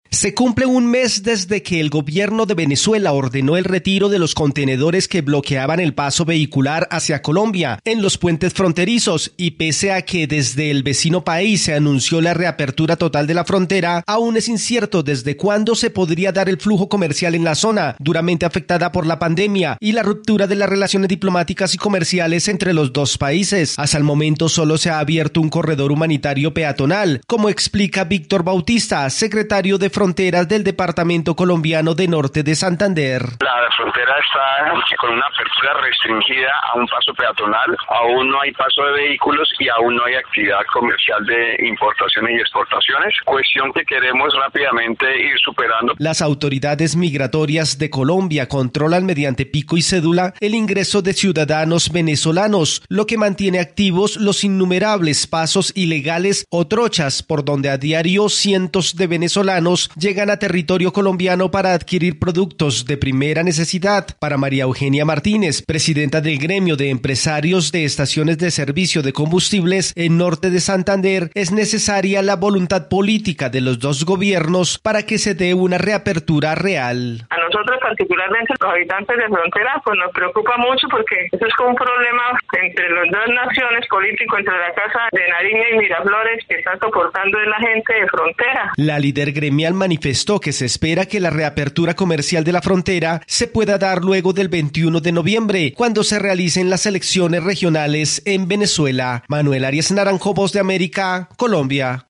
Pese al anuncio de la reapertura en la frontera colombo-venezolana, las restricciones impuestas aún no permiten la reactivación comercial entre las dos naciones. Desde Colombia informa el corresponsal de la Voz de América